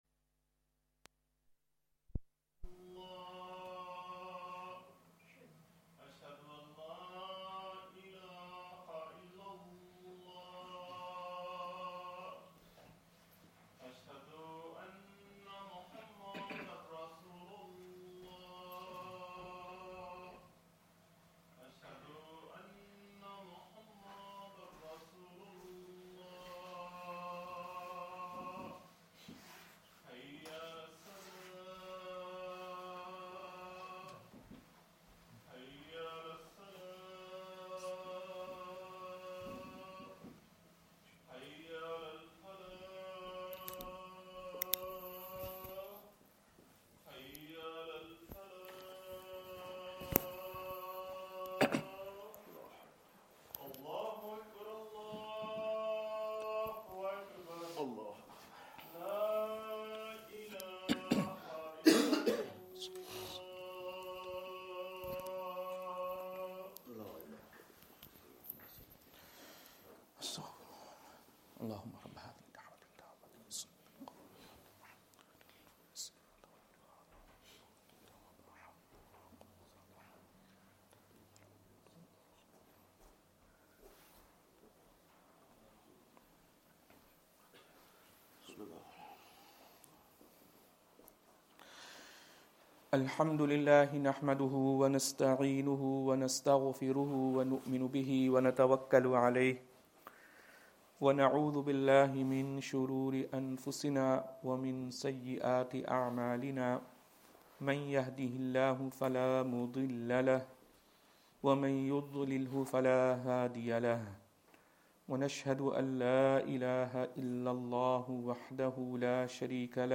Madni Masjid, Langside Road, Glasgow